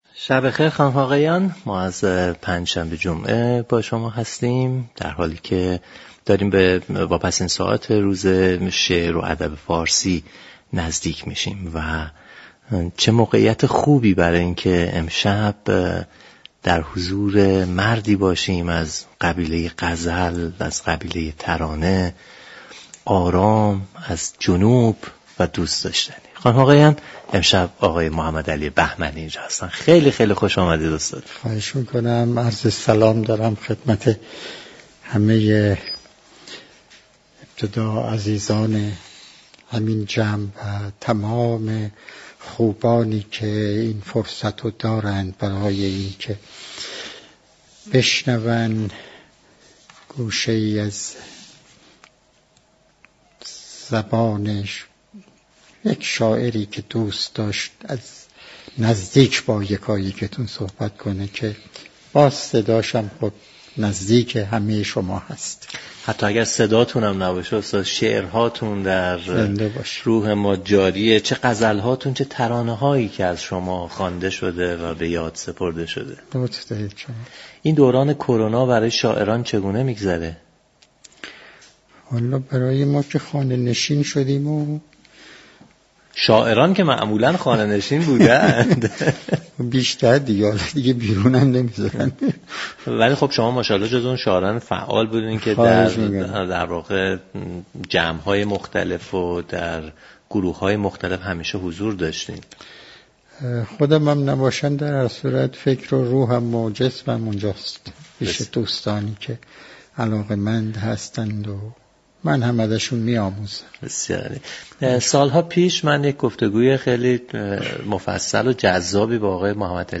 محمد علی بهمنی شاعر و غزلسرای ایرانی در برنامه پنج شنبه جمعه گفت: از سال 50 در اهواز زندگی كردم. علت علاقه ام به جنوب صداقت مردم آنجاست.